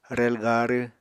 [rel garɨ] n. train